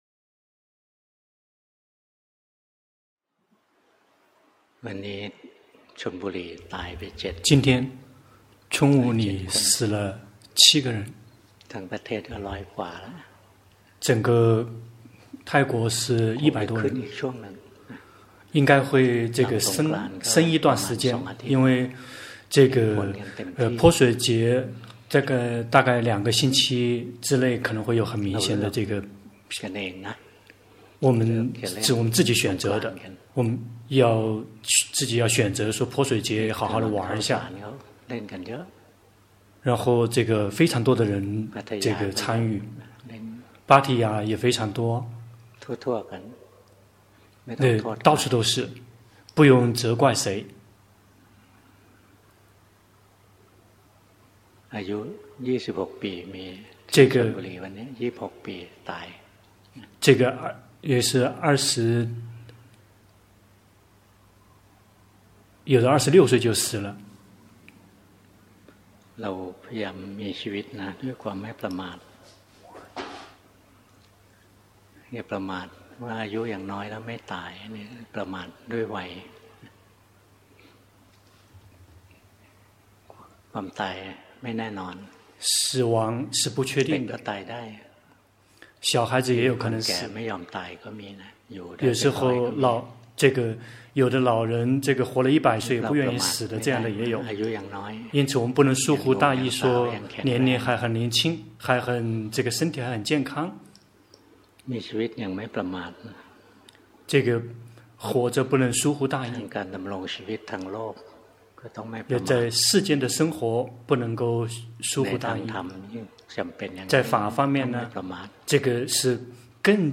法談摘錄
泰國解脫園寺 同聲翻譯